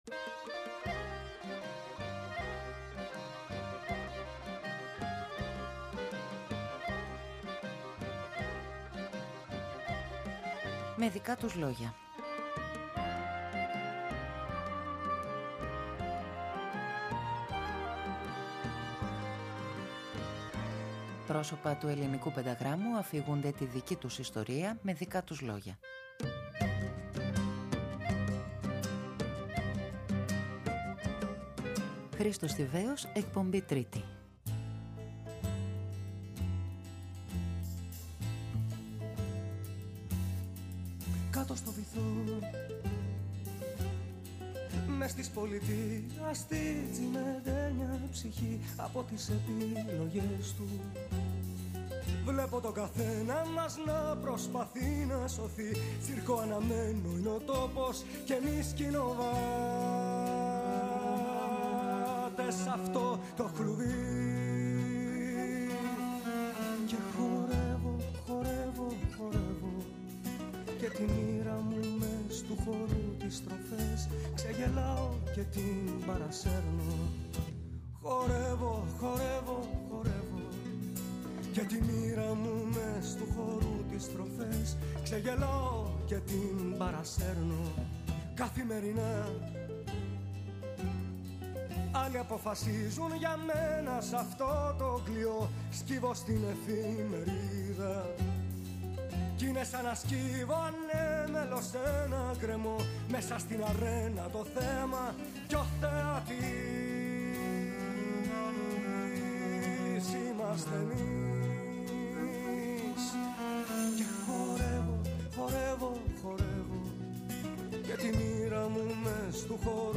Πρόσωπα του πενταγράμμου αφηγούνται τη δική τους ιστορία…
Η γνωριμία με τον Γιάννη Χαρούλη, ο πρώτος προσωπικός δίσκος, η συνεργασία με τον Θάνο Μικρούτσικο, με τον Βασίλη Παπακωνσταντίνου, την ορχήστρα Νυκτών Εγχόρδων του Δήμου Πατρέων, η γνωριμία με τον Νικόλα Πιοβάνι. Ο Χρήστος Θηβαίος αφηγείται τη δική του ιστορία με δικά του λόγια.